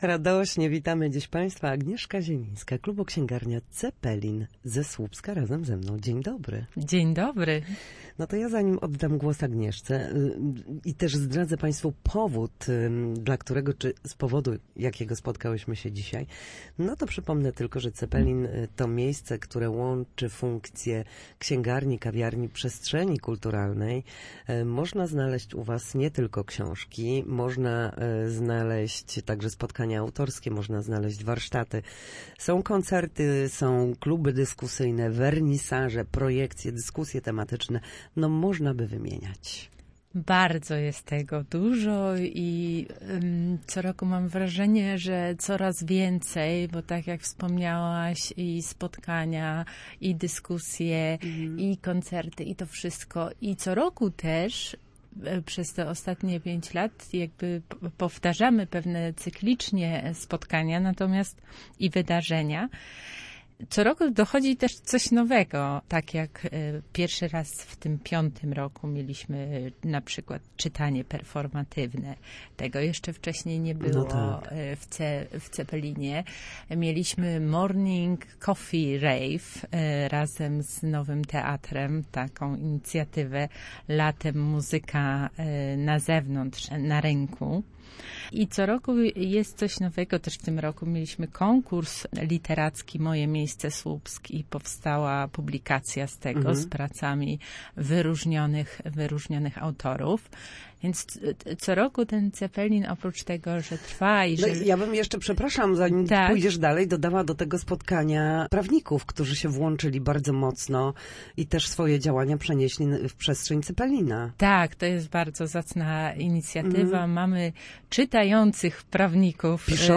Cepelin_5_urodziny_gosc.mp3